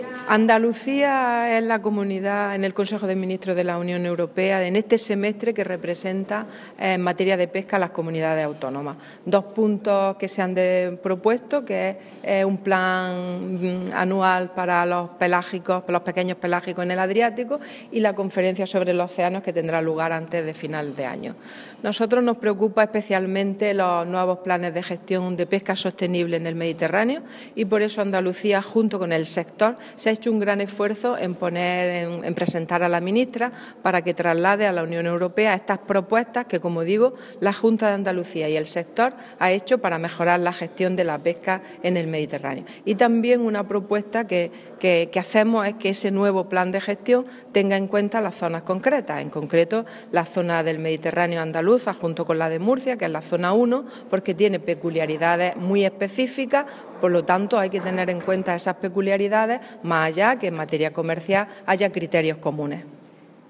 Declaraciones de Carmen Ortiz sobre gestión de la pesca del Mediterráneo